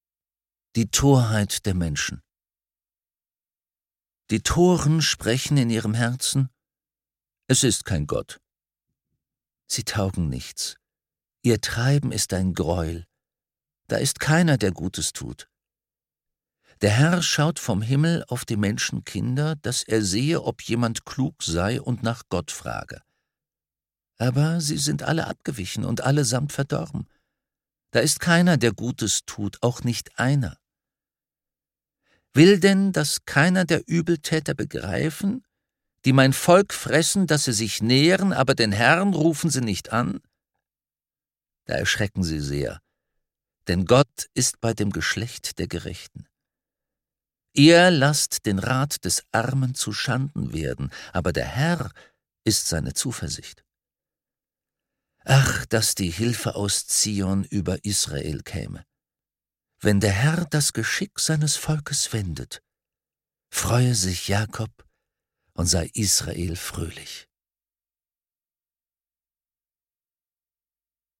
Die Psalmen. Gelesen von Rufus Beck. 4 Audio-CDs | Die Bibel